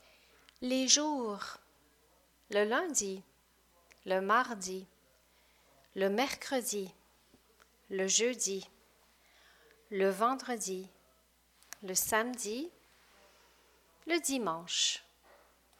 Use the list below to learn the new vocabulary and listen carefully to the French pronunciation in the audio recording.